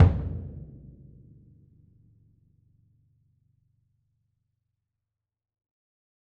BDrumNew_hit_v7_rr1_Sum.mp3